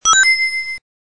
Sonic Ring